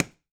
CLASP_Plastic_Close_stereo.wav